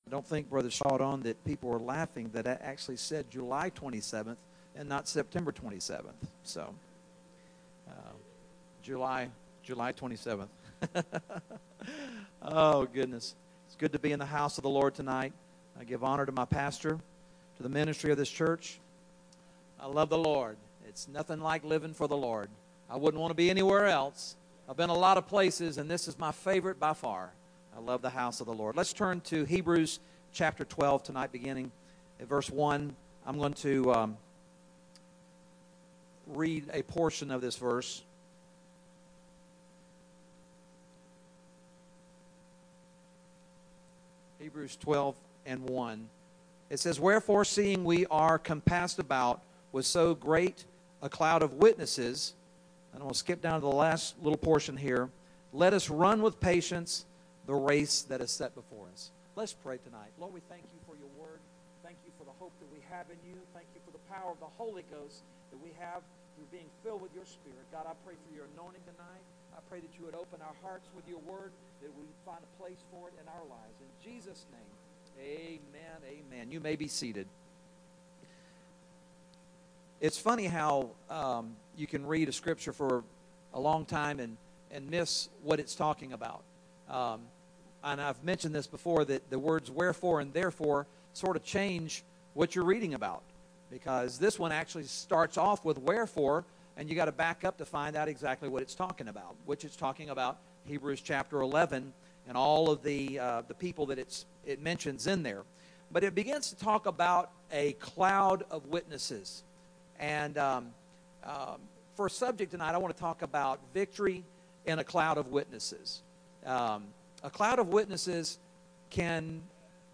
First Pentecostal Church Preaching 2019